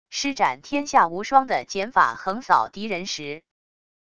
施展天下无双的锏法横扫敌人时wav音频